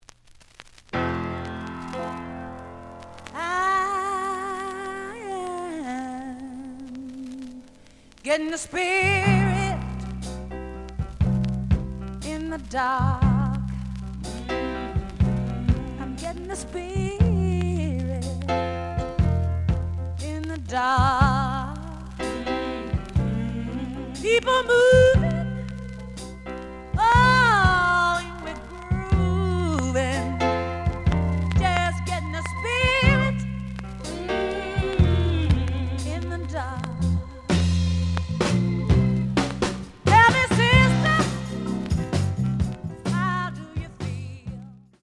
The audio sample is recorded from the actual item.
●Genre: Soul, 70's Soul
Slight edge warp.